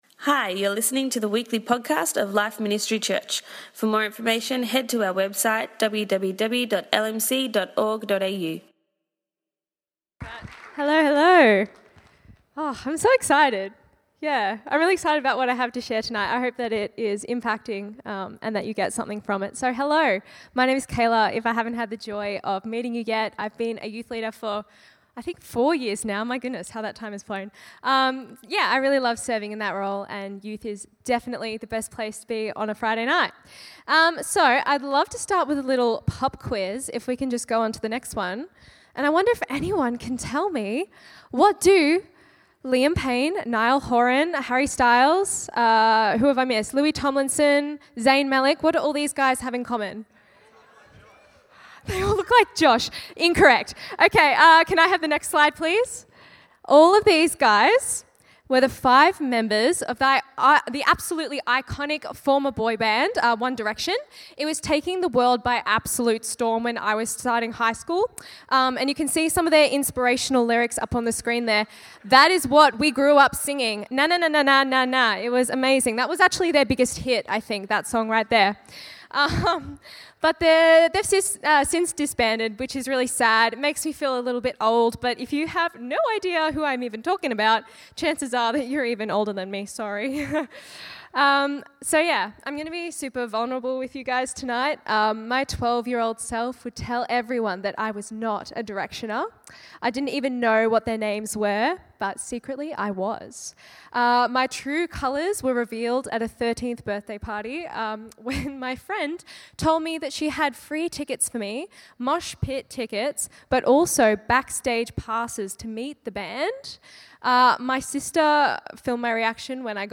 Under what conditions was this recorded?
At tonight's Youth Service